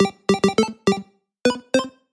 Звуки 8 бит